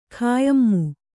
♪ khāyammu